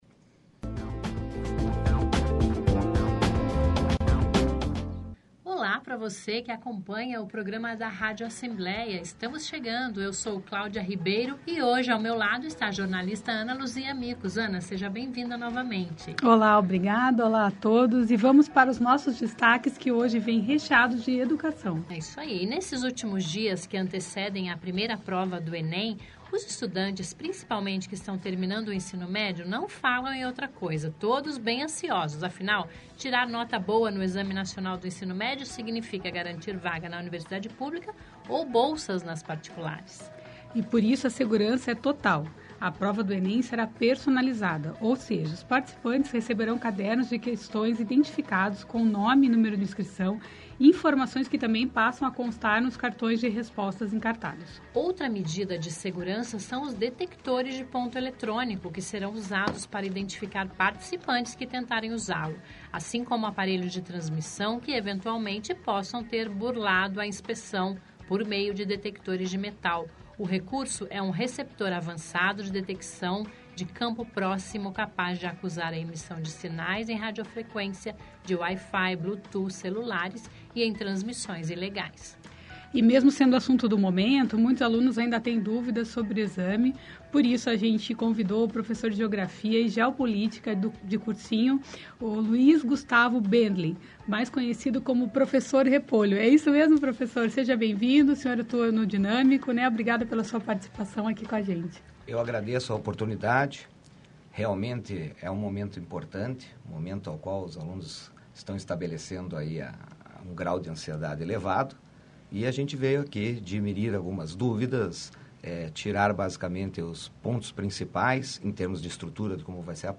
Uma entrevista com ótimas dicas com o professor de Geografia e Geopolítica